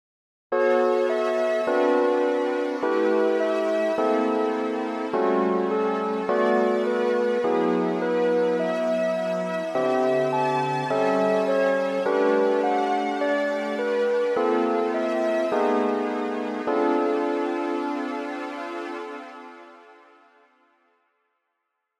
響きは「すっきり・あいまい」という感じです。
という訳で、以下の実施例は上三声に接触する位置(付加音に近い形）を織り交ぜています。